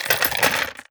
Gun Sold 001.wav